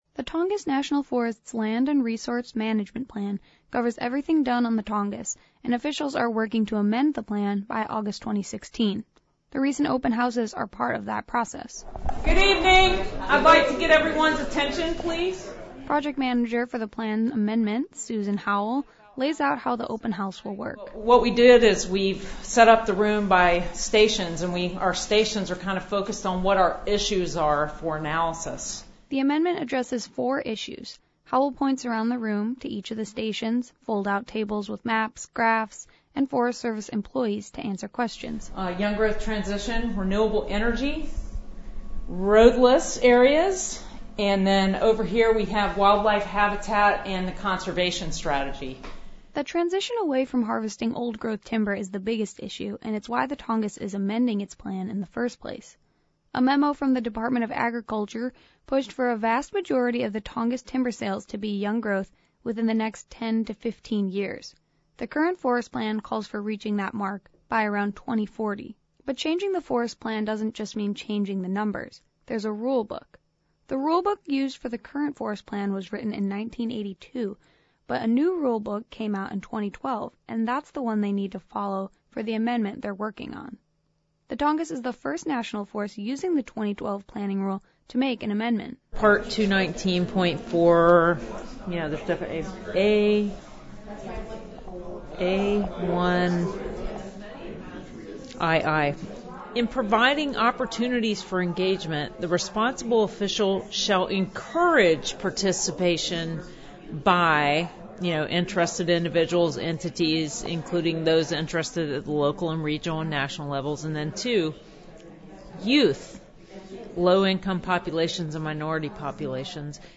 The meetings were in Juneau, Sitka and wrapped up earlier this week in Ketchikan- where there was a group of well-dressed high schoolers .